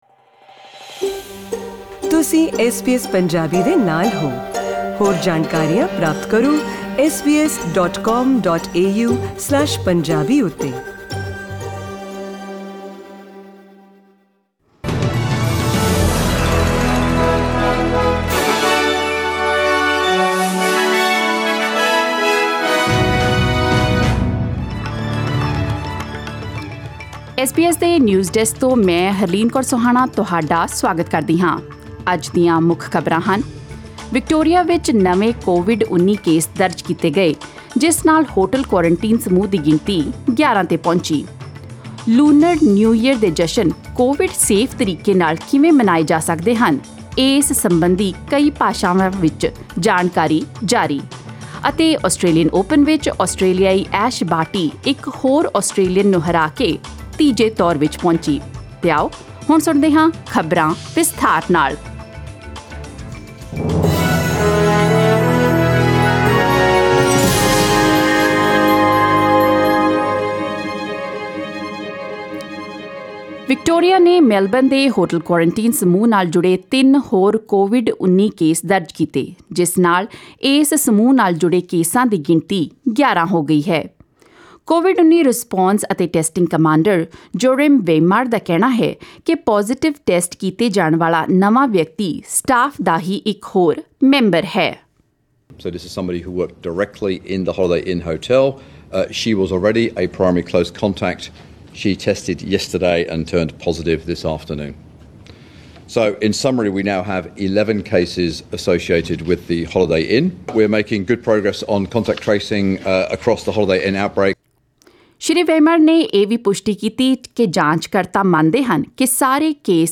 SBS Punjabi News 11 Feb: Health advice issued in many languages, encouraging COVID-safe Lunar New Year celebrations